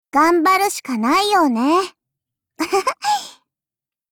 碧蓝航线:塔什干(μ兵装)语音